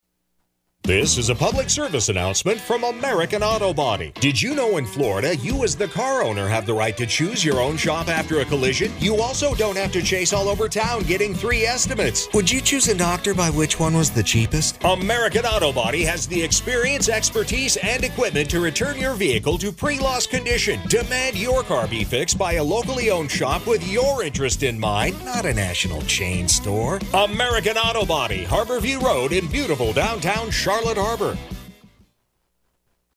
pick-your-own-body-shop-wccf-radio.mp3